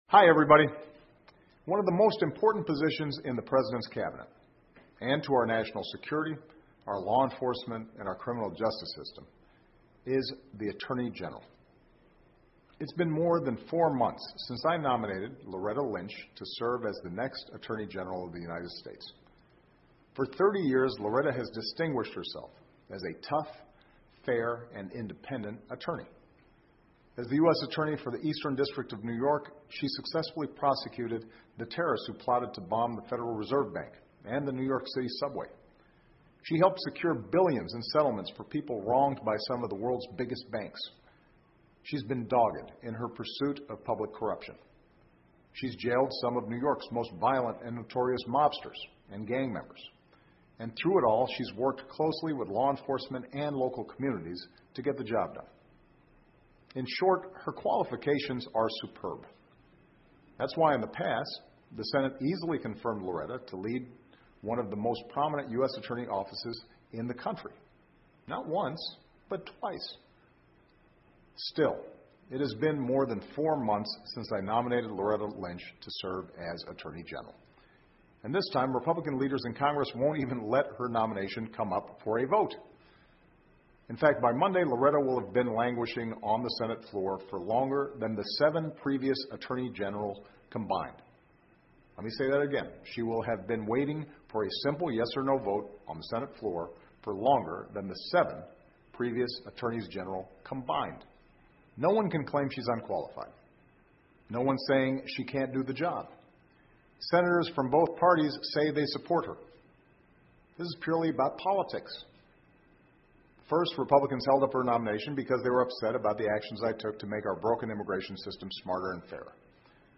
奥巴马每周电视讲话：总统要求尽快投票通过对洛丽塔·林奇的任命 听力文件下载—在线英语听力室